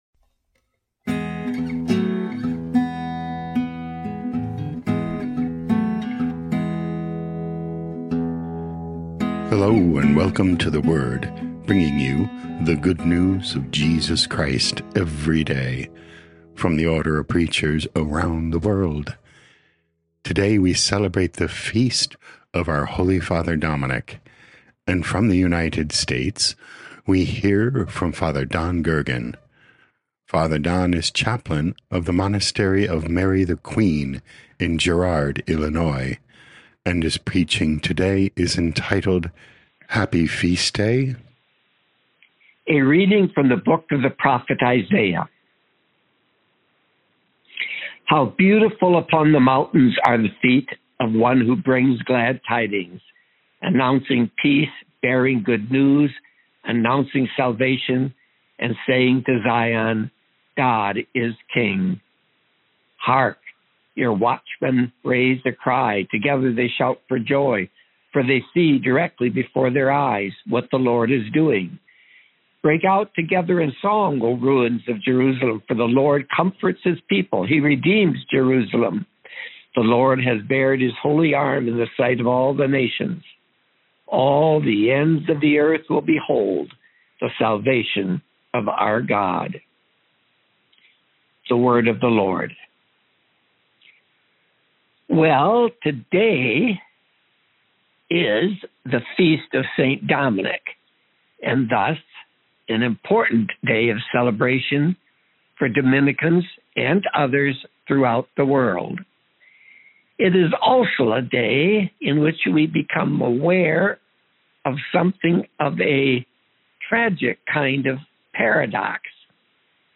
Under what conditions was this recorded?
8 Aug 2025 Happy Feast Day Podcast: Play in new window | Download For 8 August 2025, The Feast for St. Dominic, based on Isaiah 52:7-10, sent in from Girard, Illinois, USA.